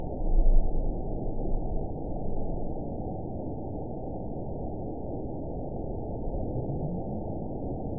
event 922747 date 03/25/25 time 06:08:06 GMT (1 month, 1 week ago) score 9.38 location TSS-AB10 detected by nrw target species NRW annotations +NRW Spectrogram: Frequency (kHz) vs. Time (s) audio not available .wav